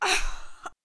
summoner_die2.wav